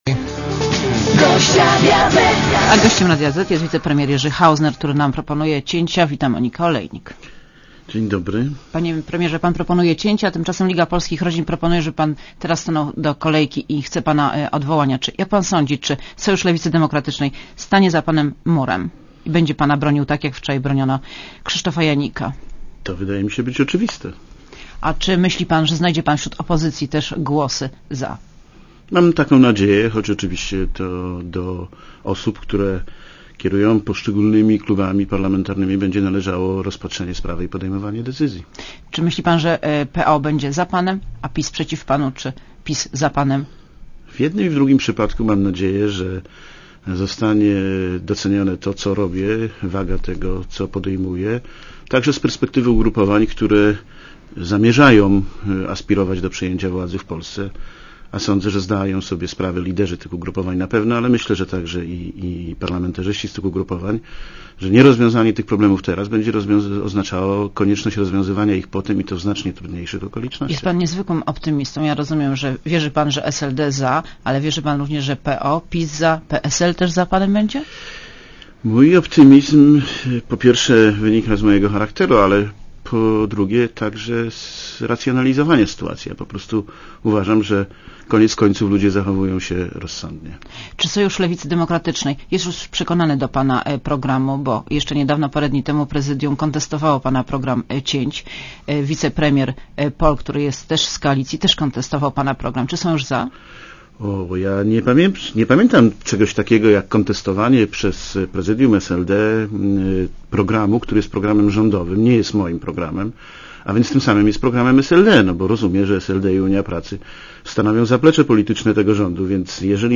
Posłuchaj wywiadu (3,25 MB) Gościem Radia Zet jest wicepremier Jerzy Hausner, który nam proponuje cięcia.